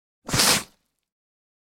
دانلود صدای گرگ 7 از ساعد نیوز با لینک مستقیم و کیفیت بالا
جلوه های صوتی